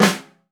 T5LV  FLAM.wav